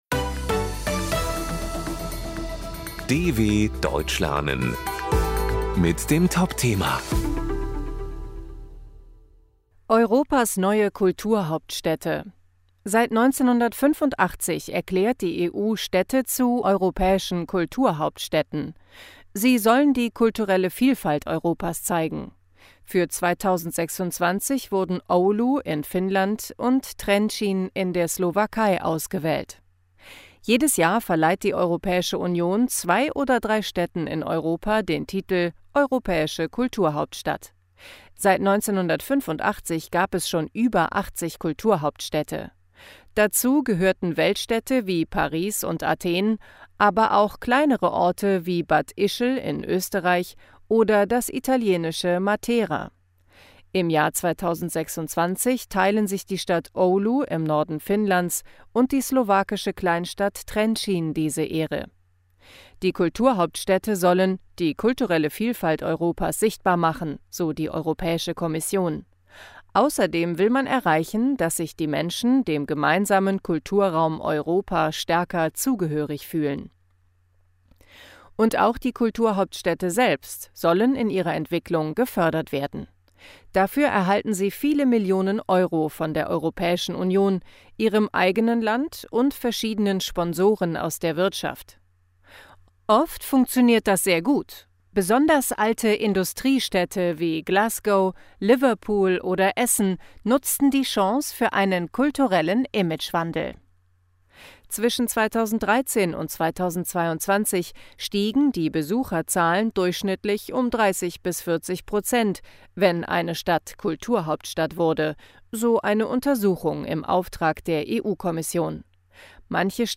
B1 | Deutsch für Fortgeschrittene: Deutsch lernen mit Realitätsbezug: aktuelle Berichte der Deutschen Welle – leicht verständlich und mit Vokabelglossar.